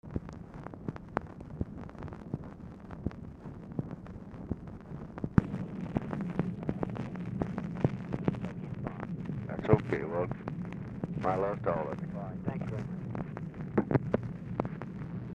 Mansion, White House, Washington, DC
Telephone conversation
Dictation belt